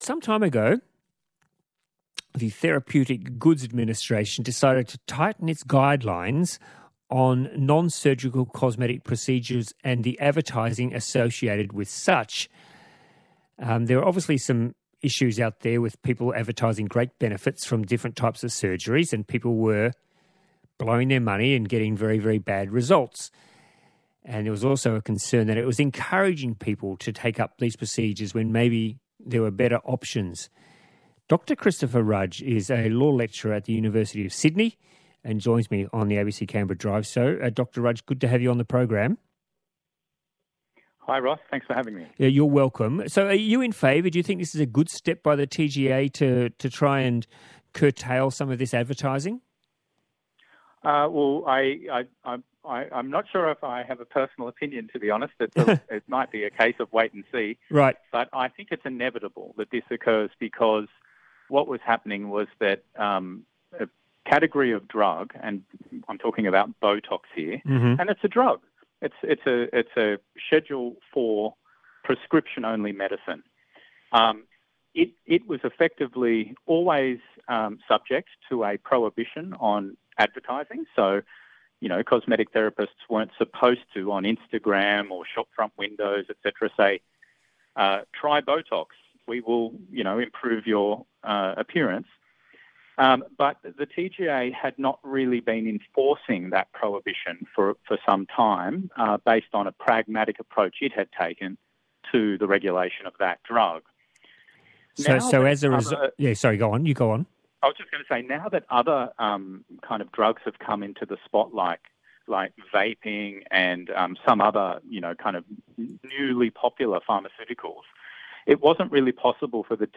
But I was again recently interviewed on ABC Drive Canberra about these reforms.